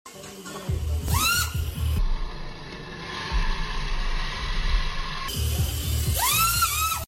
Drift Reverse Entry 🔥 Push Sound Effects Free Download